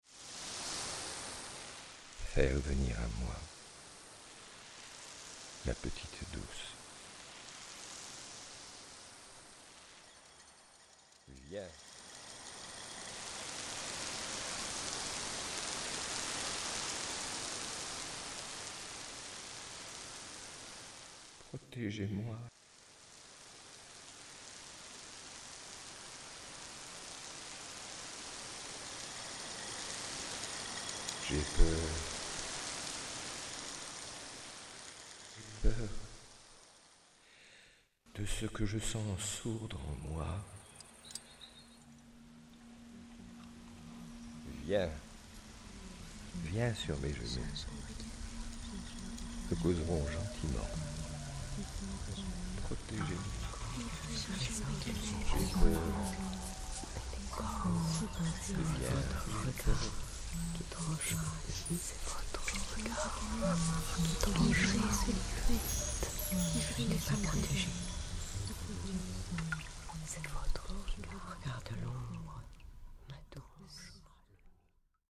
Cette composition abouti à un film vidéo tourné en représentation et en extérieur.
Cette création conjugue plusieurs espaces, plusieurs arts: les images et sons, les corps, la voix.
Corps-Voix :